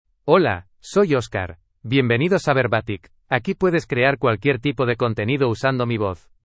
MaleSpanish (Spain)
Oscar is a male AI voice for Spanish (Spain).
Voice sample
Male
Oscar delivers clear pronunciation with authentic Spain Spanish intonation, making your content sound professionally produced.